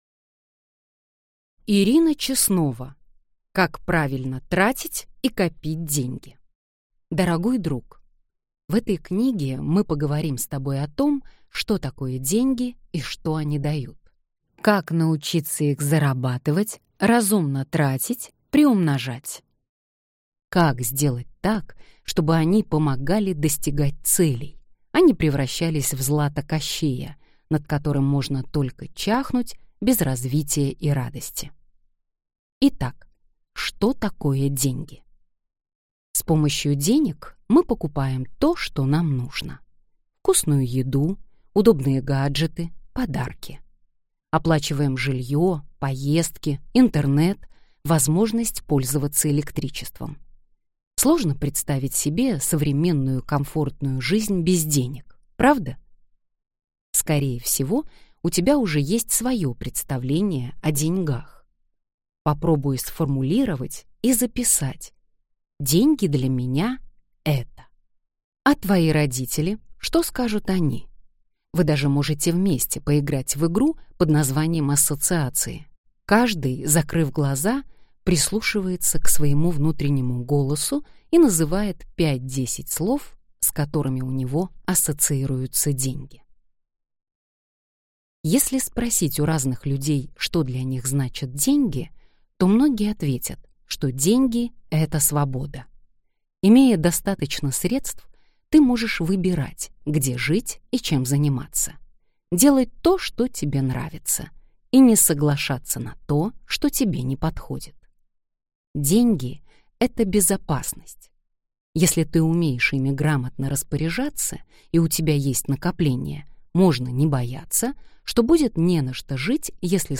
Аудиокнига Как правильно тратить и копить деньги | Библиотека аудиокниг